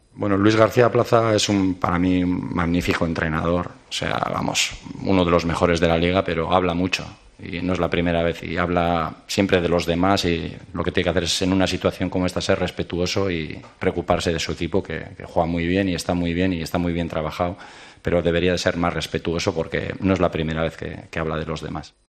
El entrenador del Almería se mostró molesto en rueda de prensa con unas palabras del técnico del Alavés.